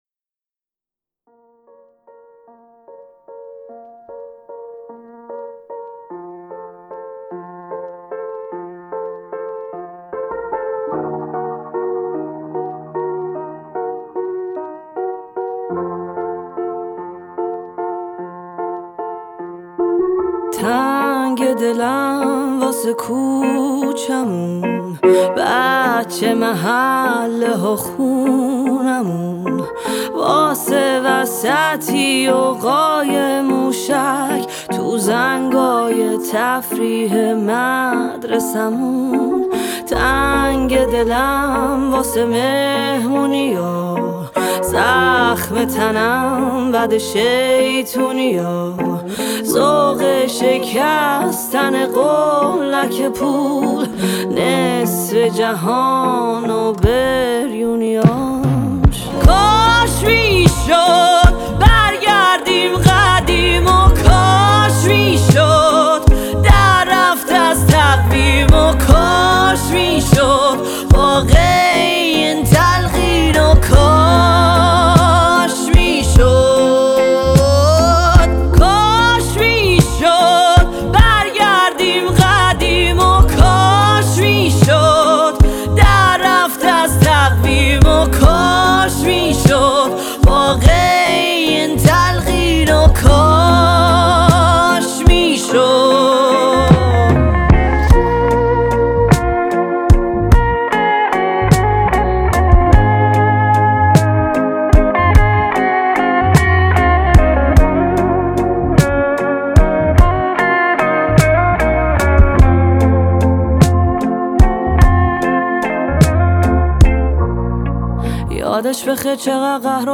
سبک پاپ احساسی